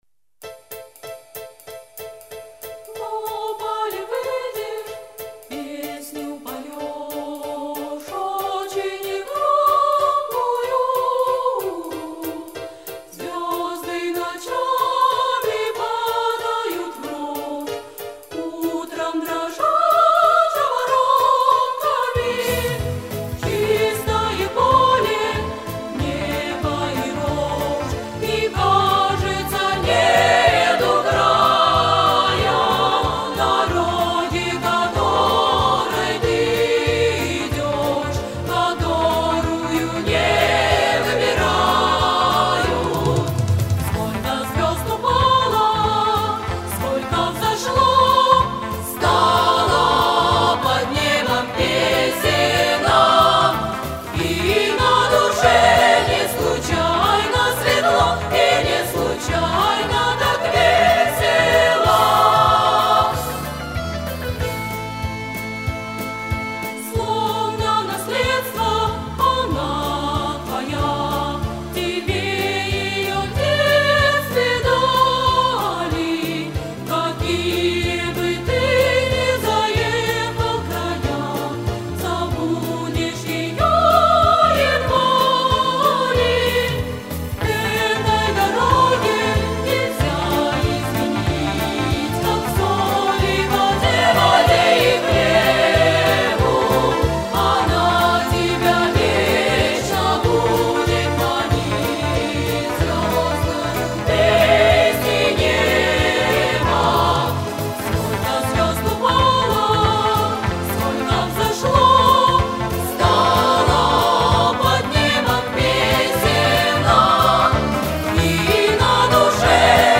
Редкий вариант в женском исполнении.
Это какой то хор